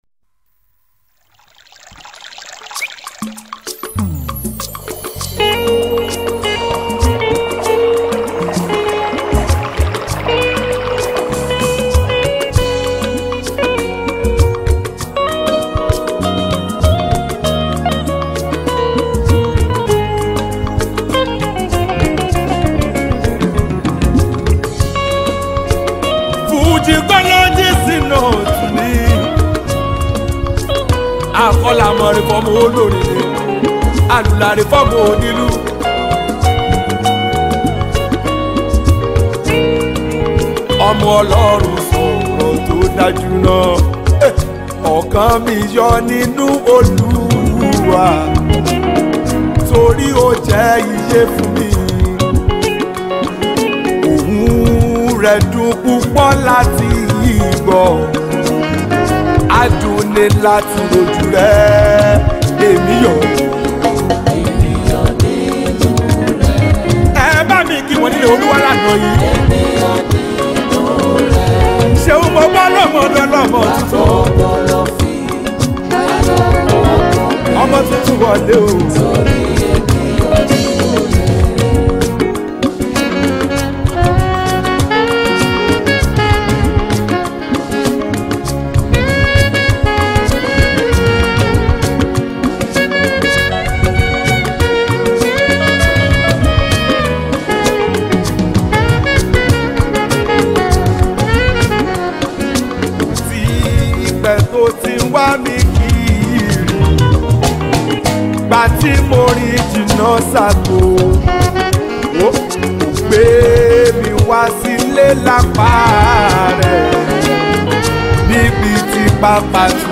Nigerian Yoruba Fuji Song